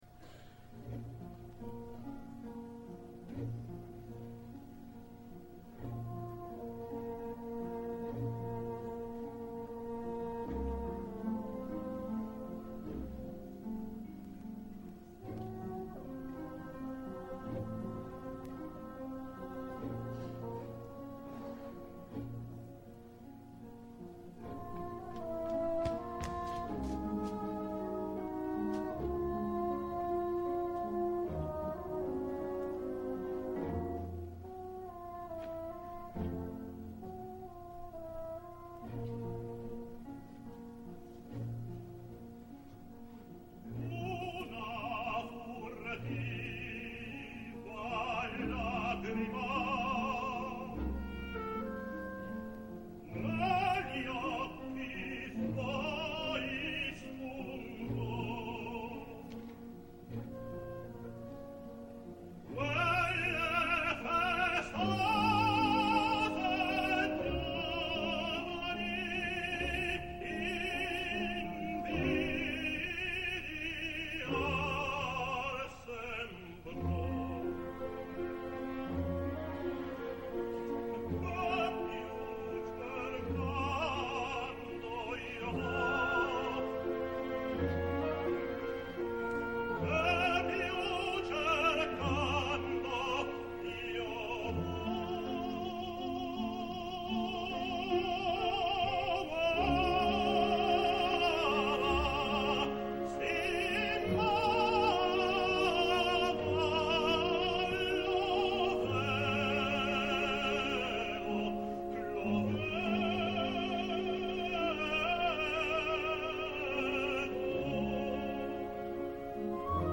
and became a tenor.